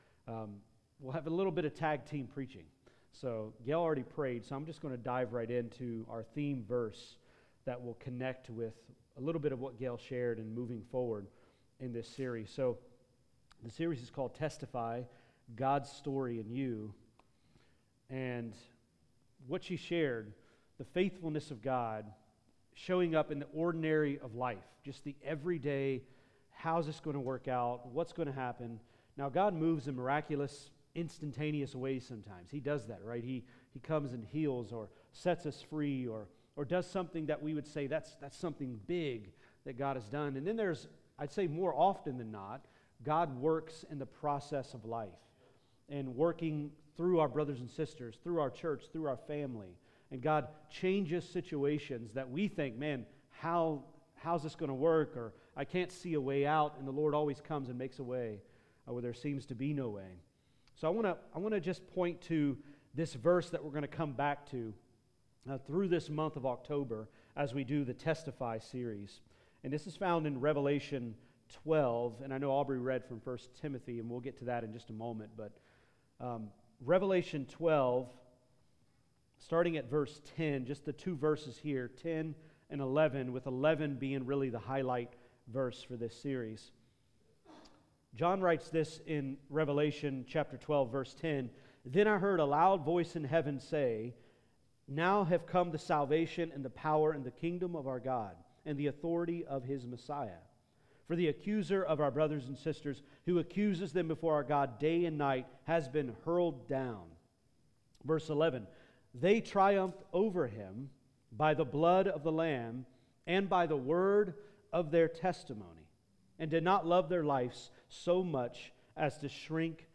Sermons | Florence Alliance Church